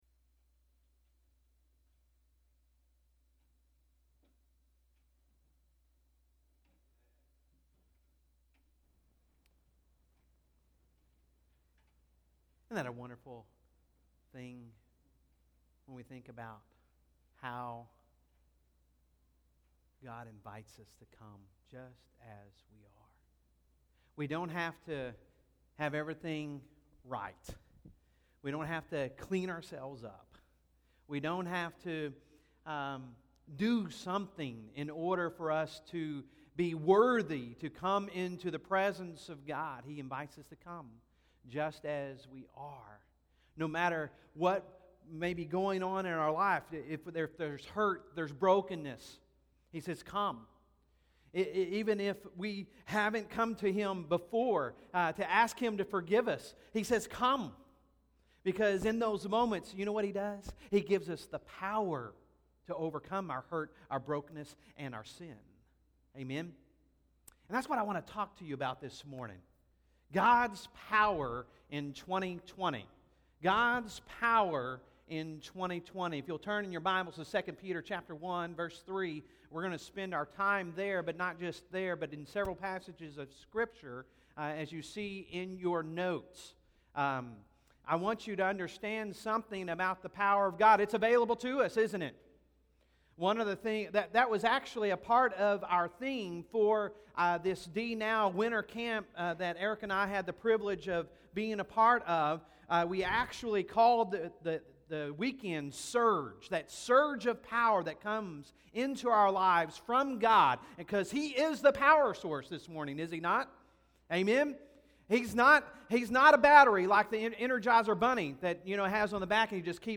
Sunday Sermon January 5, 2020